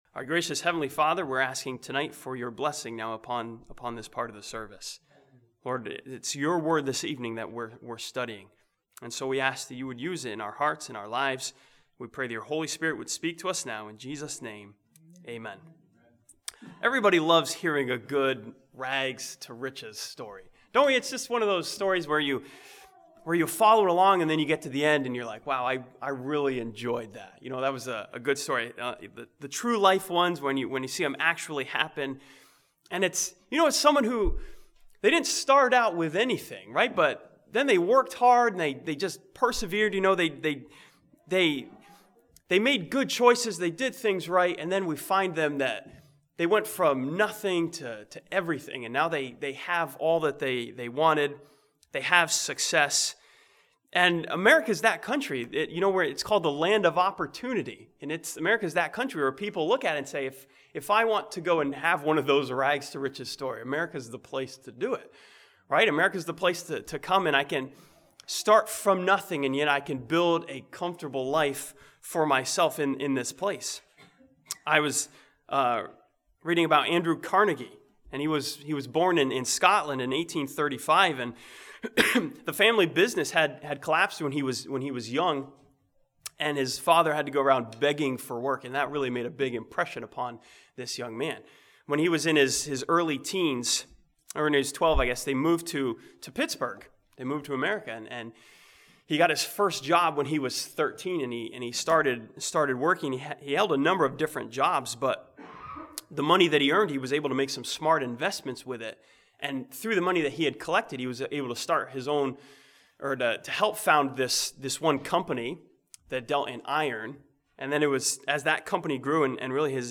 Sunday PM